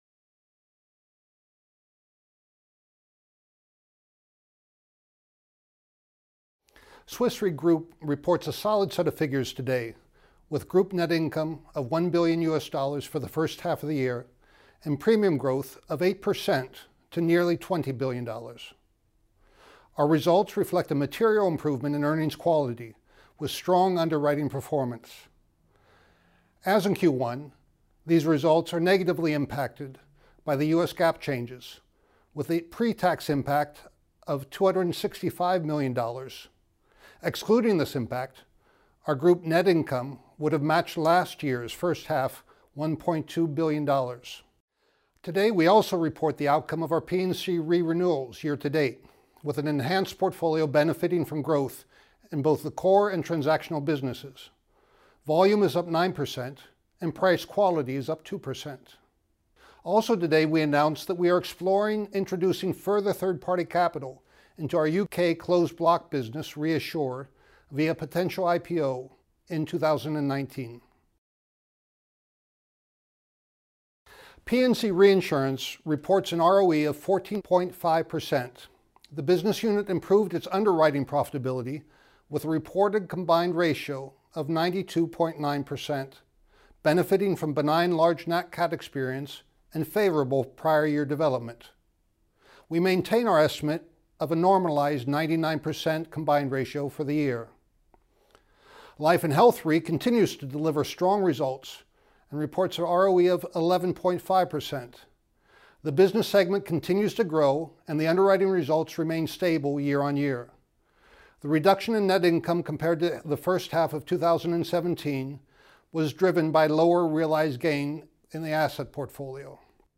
Audio of Half-year 2018 Video Presentation
2018_hy_analysts_audio.mp3